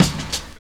99 KICK.wav